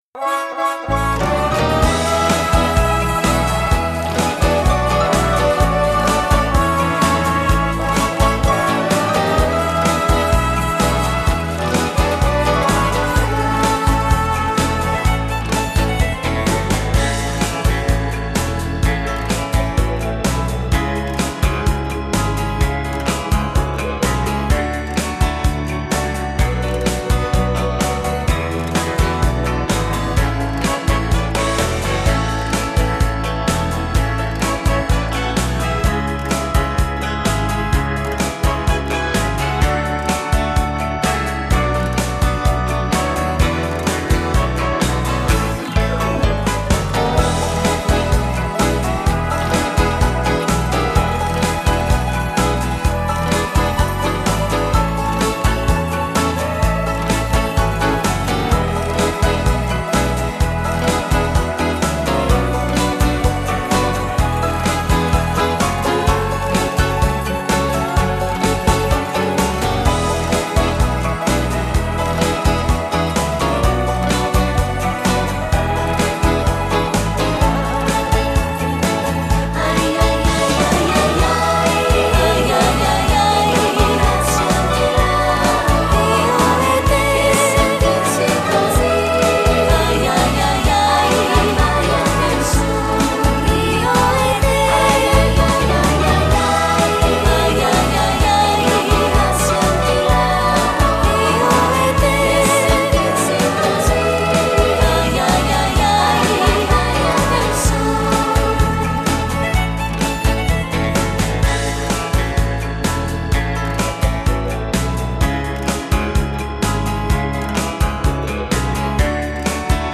Genere: Beguine
Scarica la Base Mp3 (3,71 MB)